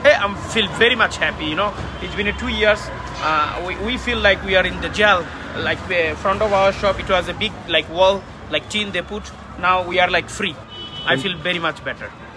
A local business owner noted that the two-year reconstruction period had kept customers away, as the road closure made it difficult for them to access shops along the street.